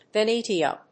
ヴェニーシャ； ヴェネシア； ベニーシャ； ベネシア